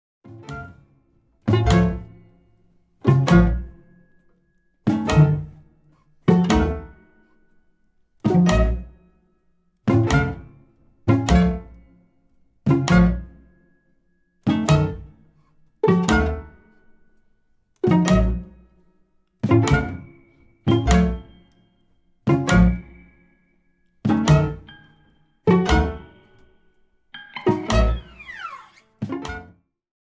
guitar/composition
piano
saxophone/clarinet
cello
drums
Sie ist sperrig, sie ist ambitioniert, sie ist großartig.